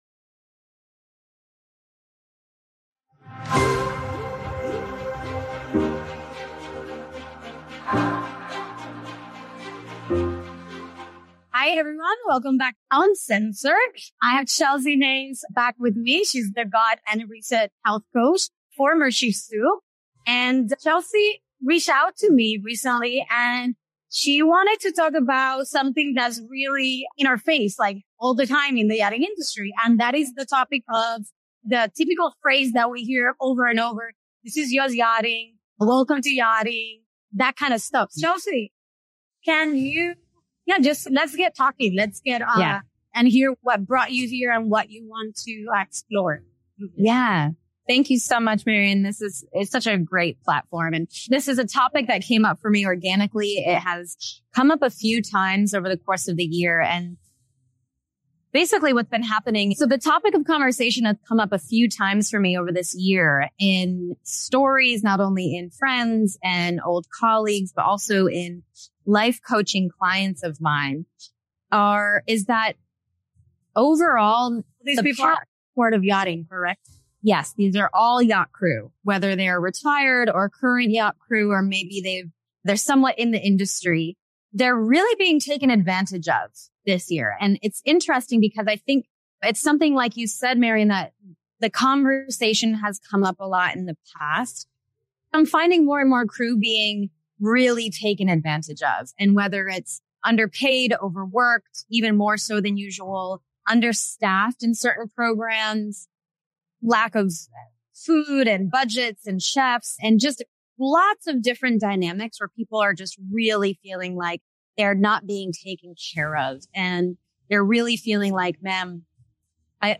This candid chat is a call to collective healing, encouraging individuals to step into their power, amplify their voices, and catalyze positive change within the yachting industry.